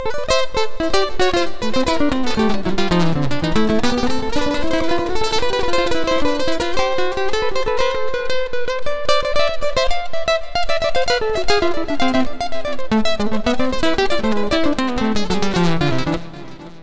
Ala Brzl 2 Gts Wet-A.wav